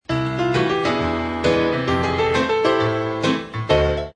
There's a distinctive left-hand pattern in
this style of piano playing, also a word meaning "walk"